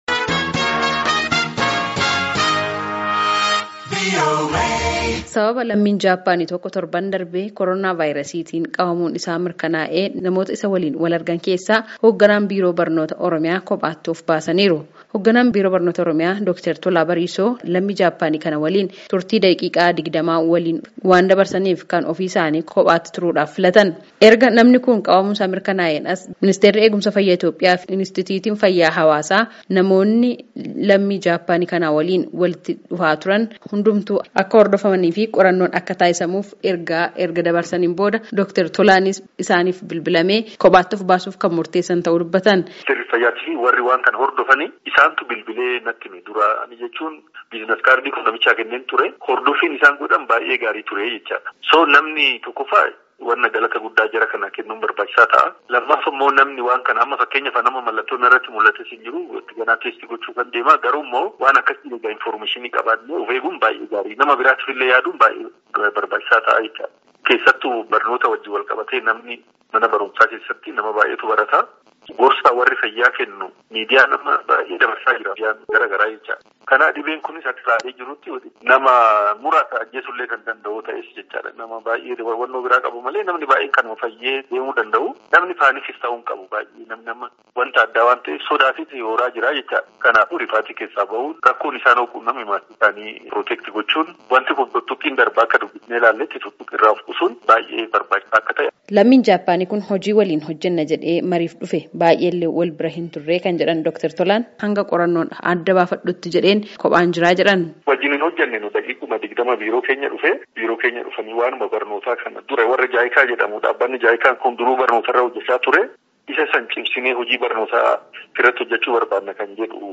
Dhimma Koronaa Vaayrasii Laalchisee Marii Hoogganaa Biiroo Barnoota Oromiyaa Wajjin